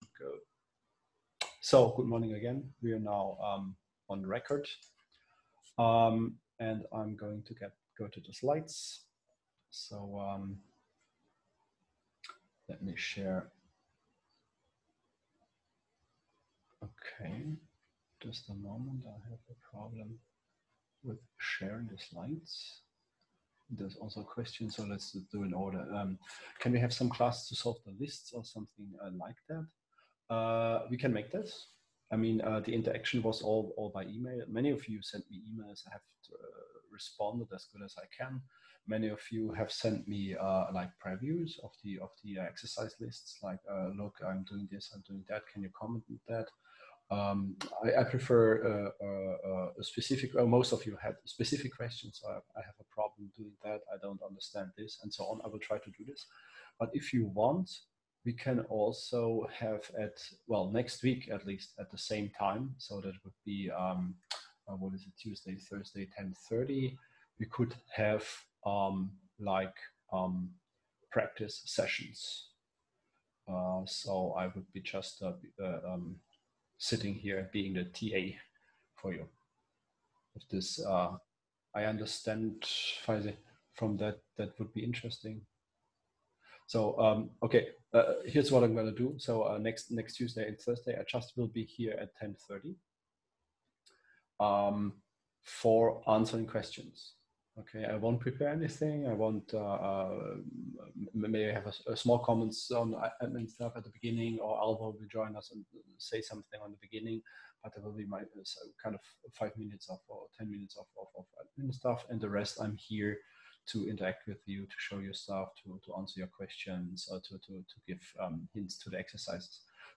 Online lecture